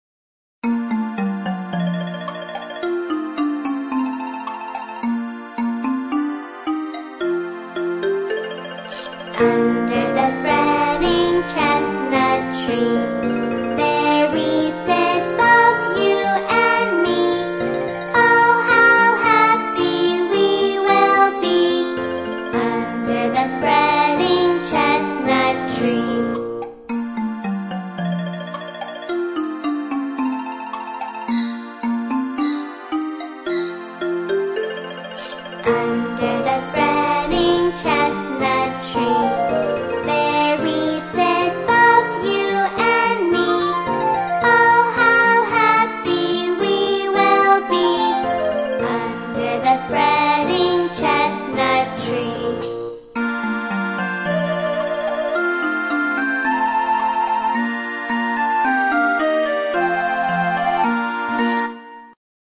在线英语听力室英语儿歌274首 第242期:Under Spreading Chestnut Tree的听力文件下载,收录了274首发音地道纯正，音乐节奏活泼动人的英文儿歌，从小培养对英语的爱好，为以后萌娃学习更多的英语知识，打下坚实的基础。